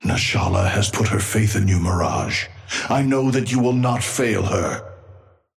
Amber Hand voice line - Nashala has put her faith in you, Mirage.
Patron_male_ally_mirage_start_03.mp3